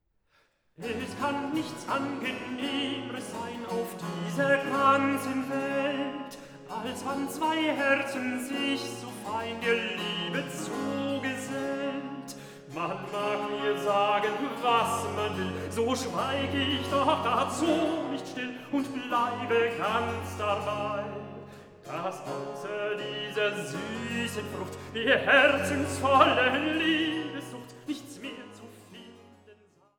Tenor
Violoncello
Theorbe
Cembalo, Polygonalspinet